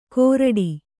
♪ kōraḍi